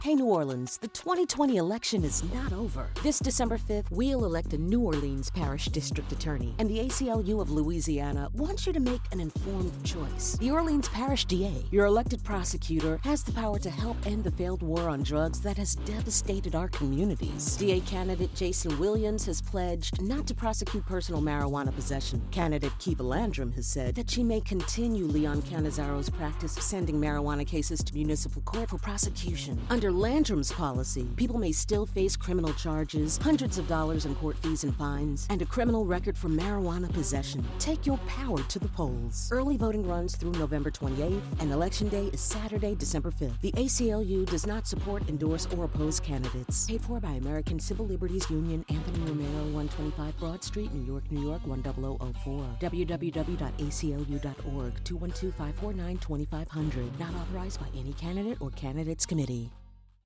The campaign includes a 60-second radio ad airing widely in the New Orleans media market and text messages to nearly 30,000 voters.
aclu_of_louisiana_radio_ad.mp3